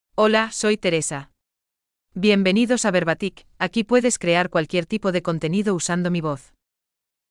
Teresa — Female Spanish (Equatorial Guinea) AI Voice | TTS, Voice Cloning & Video | Verbatik AI
Teresa — Female Spanish AI voice
Teresa is a female AI voice for Spanish (Equatorial Guinea).
Voice sample
Female
Teresa delivers clear pronunciation with authentic Equatorial Guinea Spanish intonation, making your content sound professionally produced.